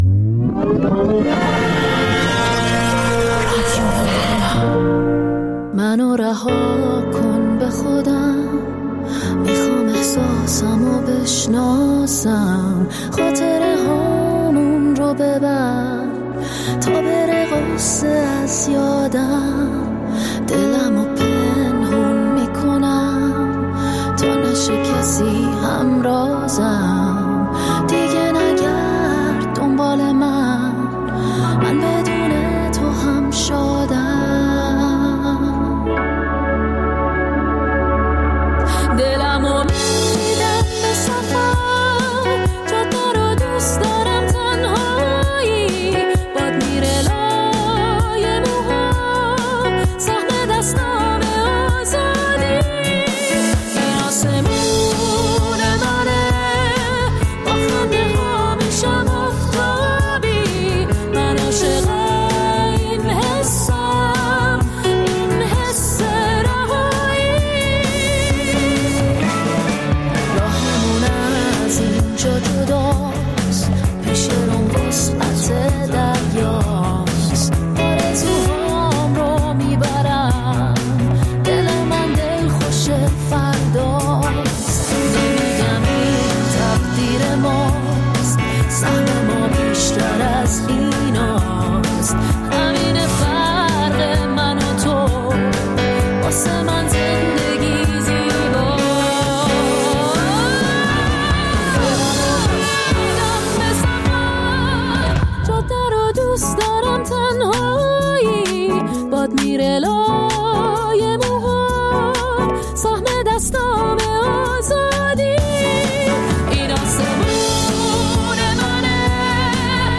منتخب موسیقی راک